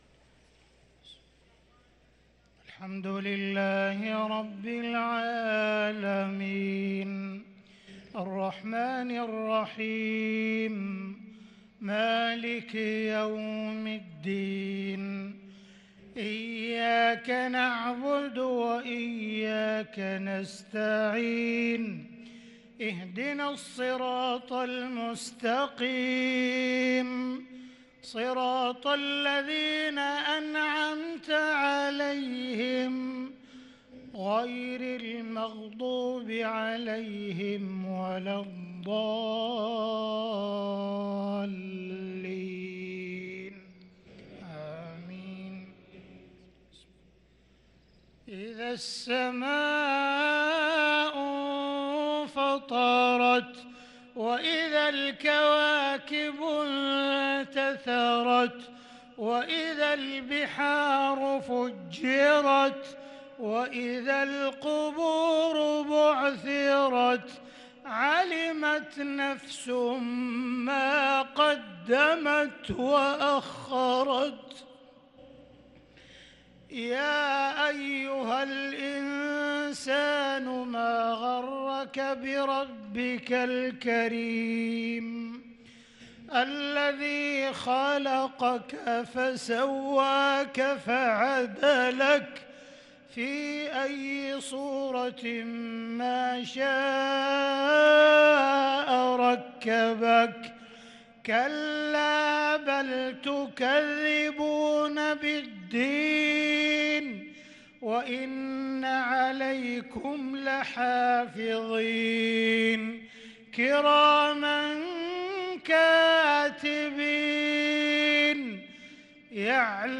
صلاة المغرب للقارئ عبدالرحمن السديس 23 رمضان 1443 هـ
تِلَاوَات الْحَرَمَيْن .